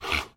sounds / mob / horse / donkey / idle2.mp3